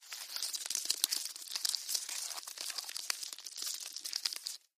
Bug Movement